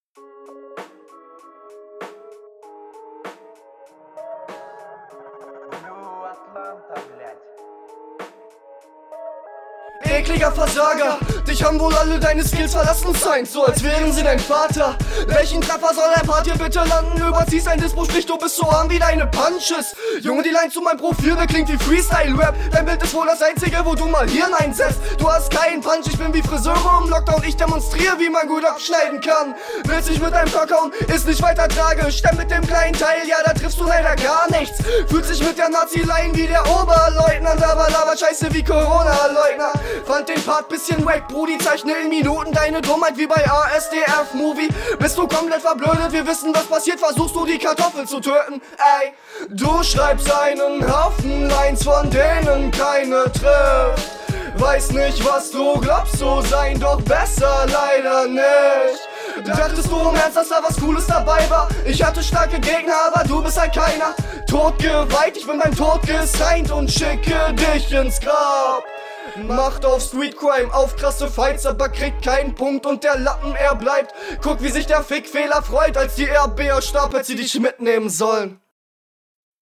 Stimmtlich kommst du höher und aggressiver als dein Gegner!